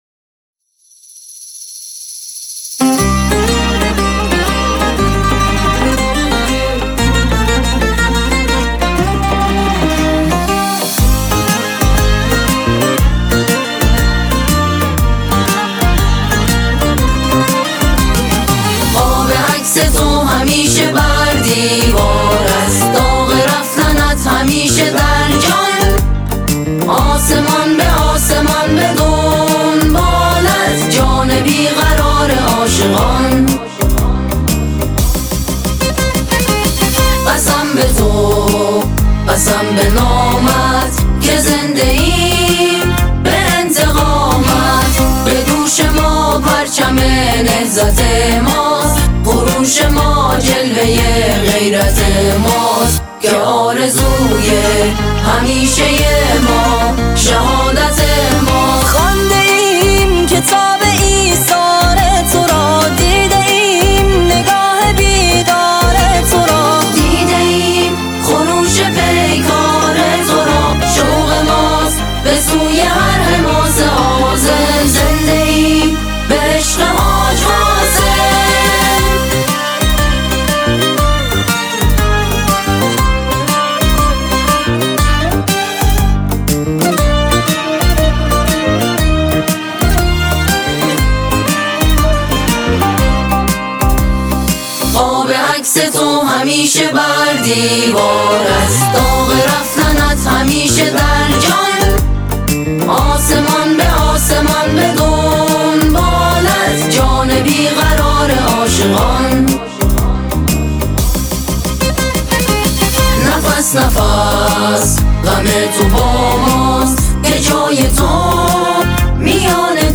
سرودهایی